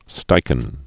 (stīkən), Edward Jean 1879-1973.